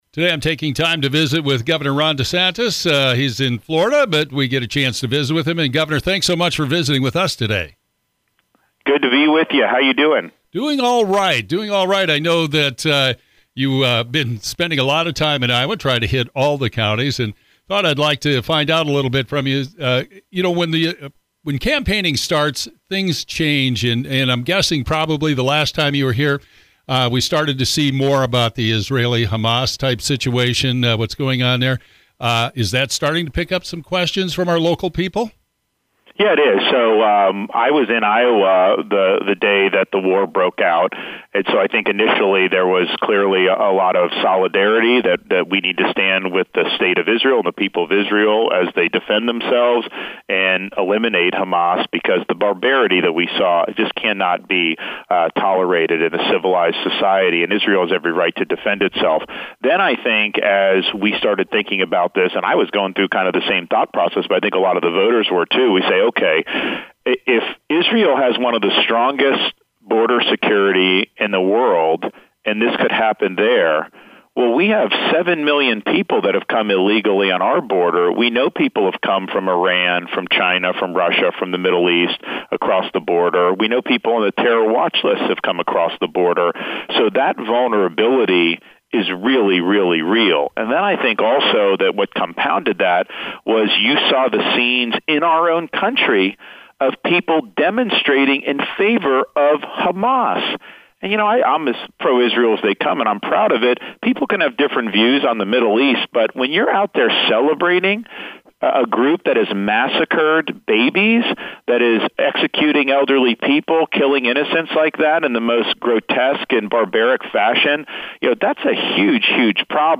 Governor Ron DeSantis, Republican Presidential Candidate talks about campaigning in Iowa and building his campaign’s base of support for the January Caucuses.